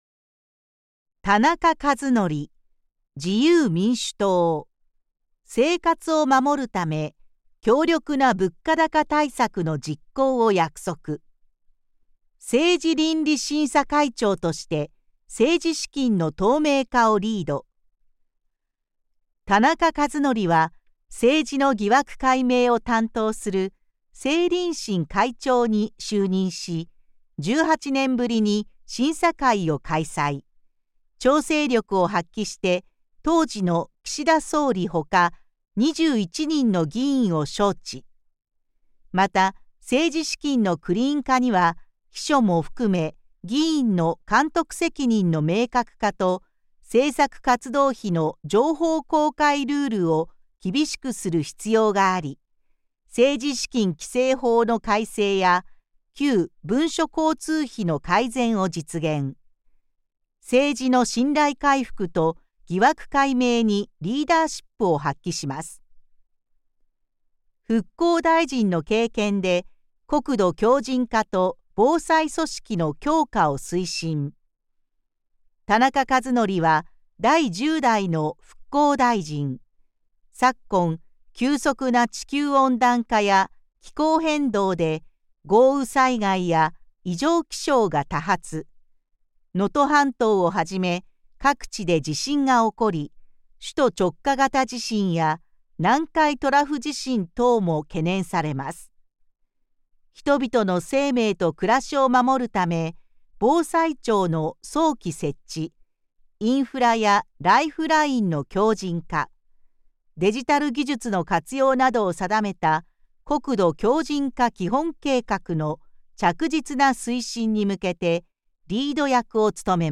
衆議院議員総選挙　候補者・名簿届出政党等情報（選挙公報）（音声読み上げ用）